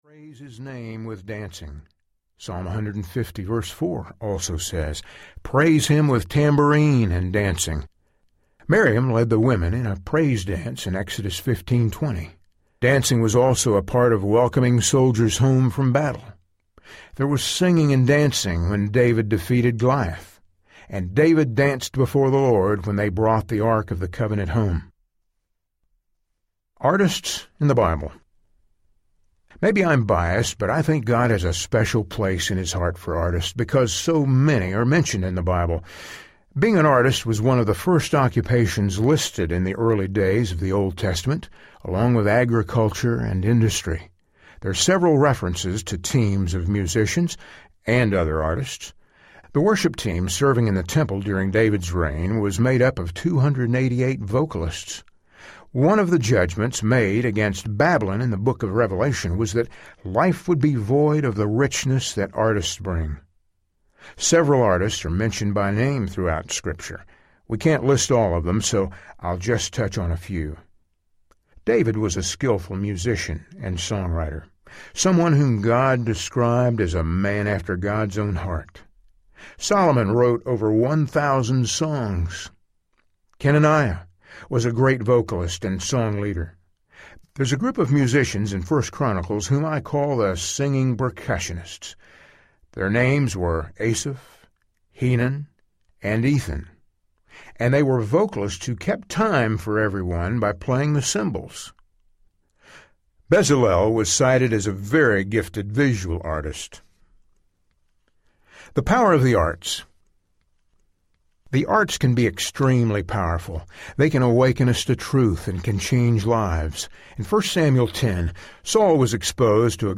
The Heart of the Artist Audiobook